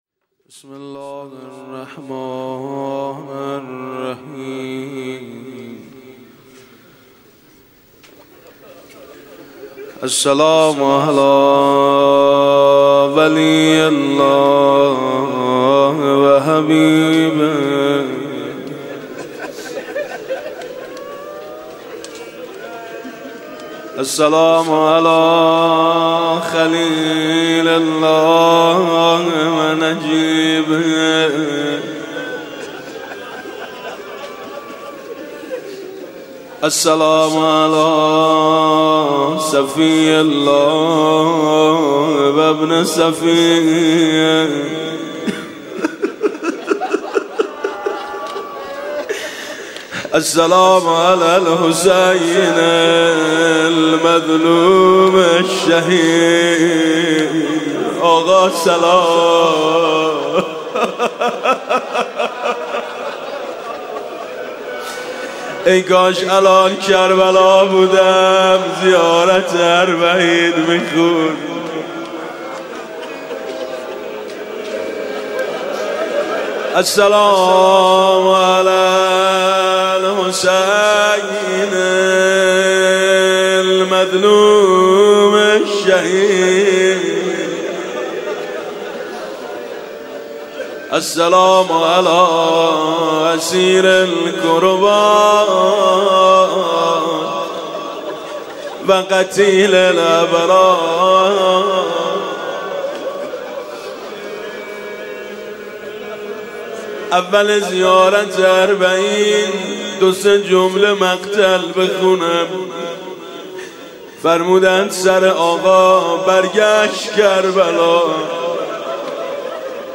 صوت/ قرائت زیارت اربعین با نوای میثم مطیعی